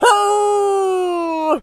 pgs/Assets/Audio/Animal_Impersonations/wolf_hurt_04.wav at master
wolf_hurt_04.wav